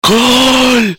Goal sound button Download
goooooool.mp3